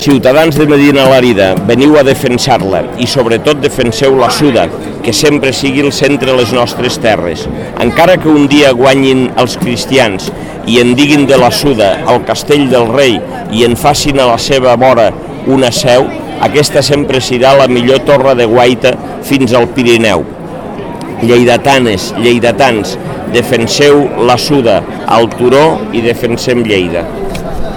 arxiu-de-so-parlament-angel-ros